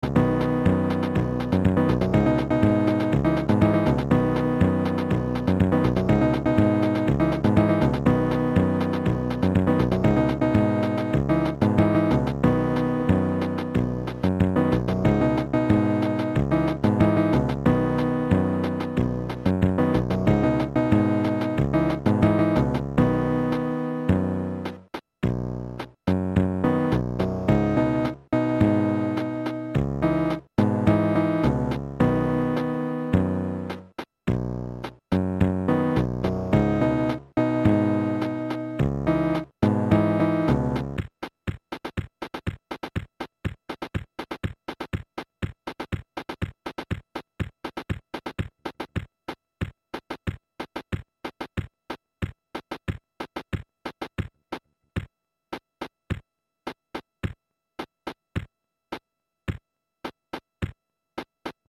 Disco